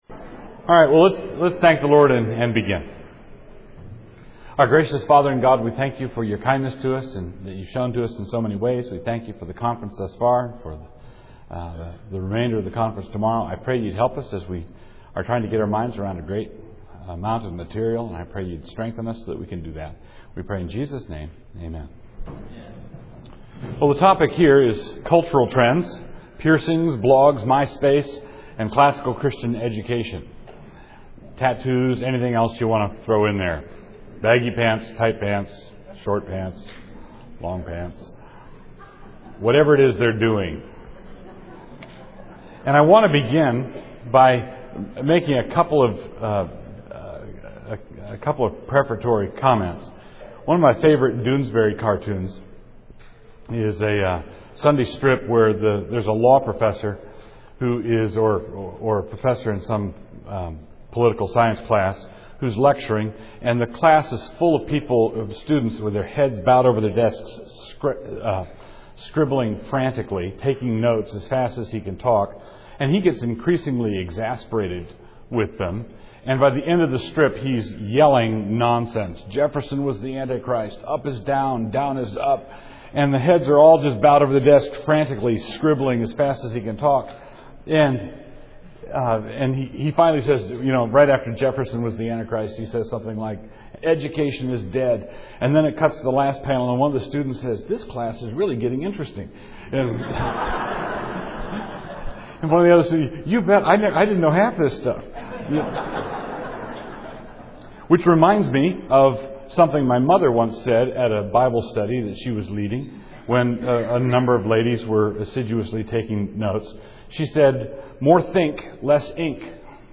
2007 Workshop Talk | 1:02:32 | Culture & Faith